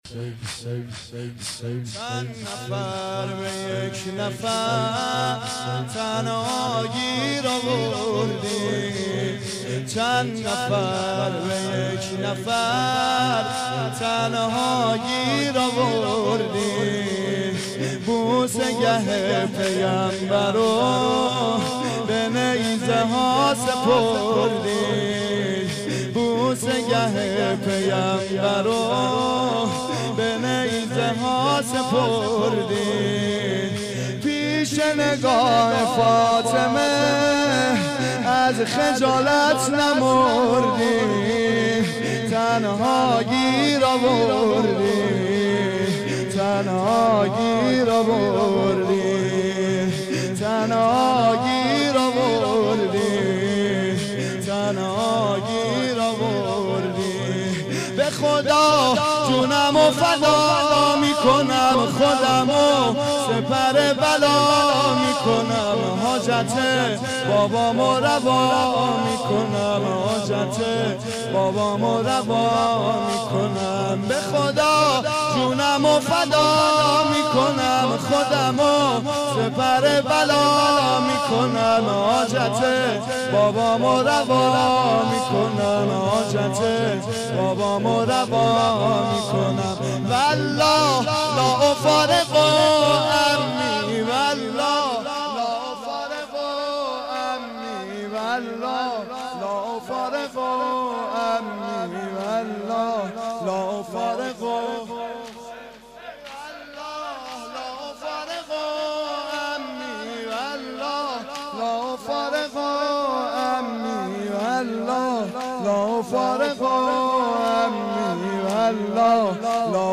زمینه شب پنجم محرم 1391